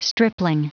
Prononciation du mot stripling en anglais (fichier audio)
Prononciation du mot : stripling